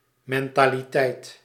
Ääntäminen
Synonyymit geestesgesteldheid denkwijze Ääntäminen Tuntematon aksentti: IPA: /ˌmɛn.taː.liˈtɛit/ Haettu sana löytyi näillä lähdekielillä: hollanti Käännös 1. mentalitet {c} Suku: f .